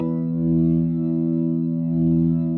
B3LESLIE E 3.wav